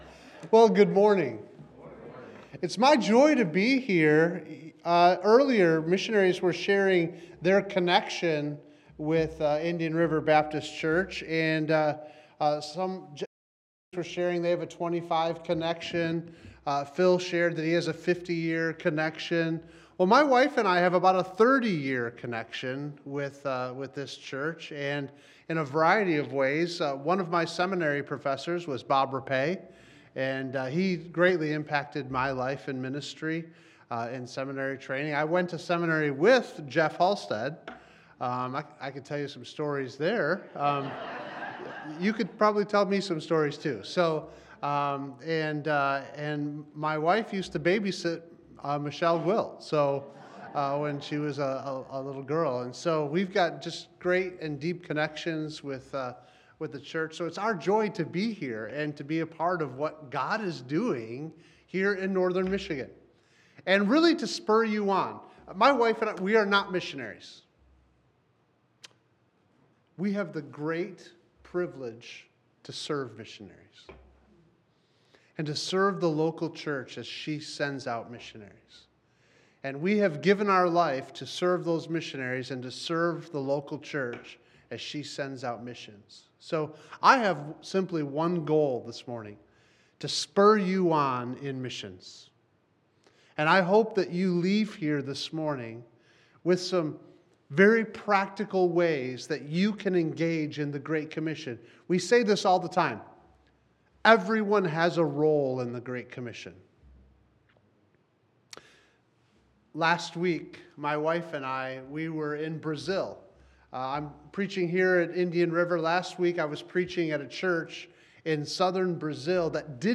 Please note that portions of audio have been removed to protect sensitive information.
Sermons
2025-Missions-Conference-Keynote-Audio.m4a